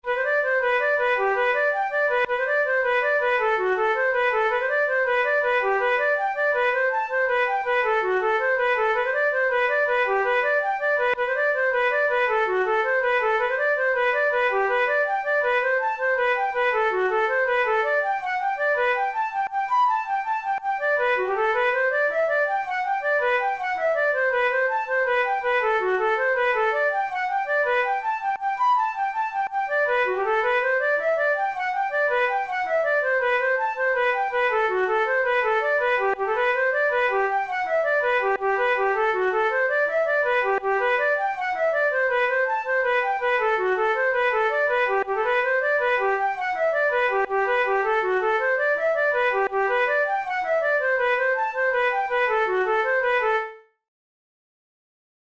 InstrumentationFlute solo
KeyG major
Time signature6/8
Tempo108 BPM
Jigs, Traditional/Folk
Traditional Irish jig